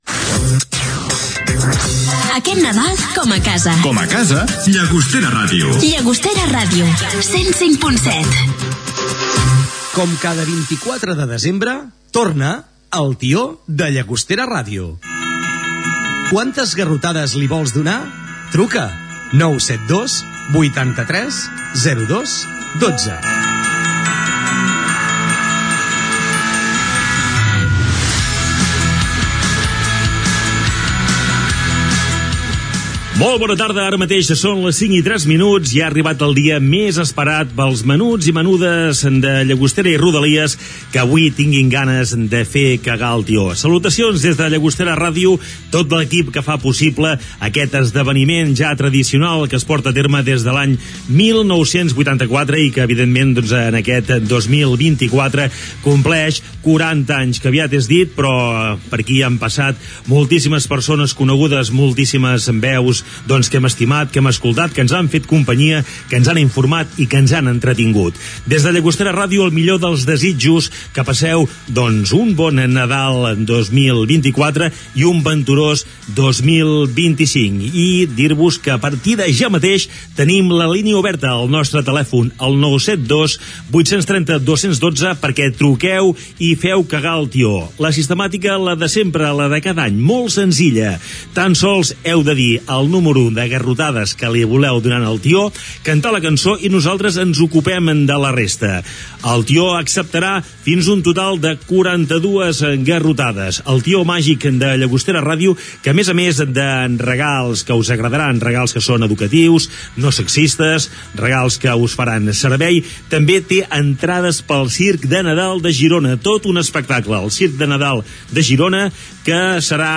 Indicatiu de la ràdio, inici del programa, que s'emet des de 1984. Invitació a participar i trucades telefòniques dels nens.
Entreteniment